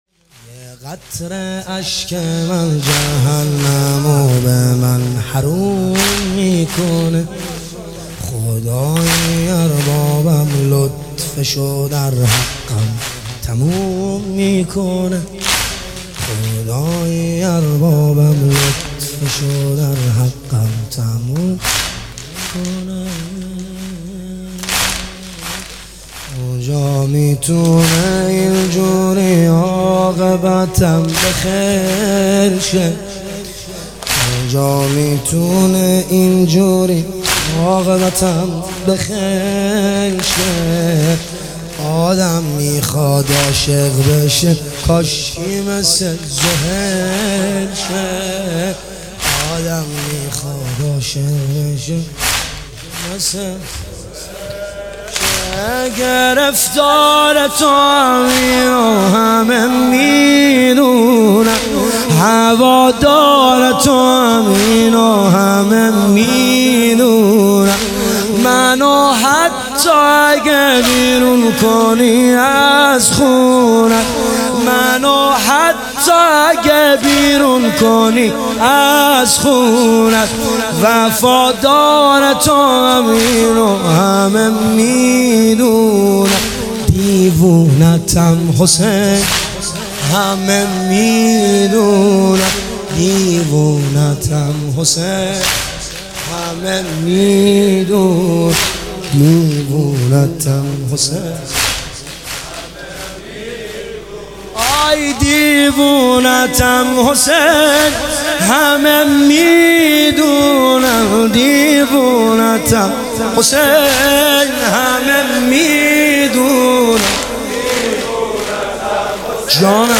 محرم 1399 | هیئت عشاق الرضا (ع) تهران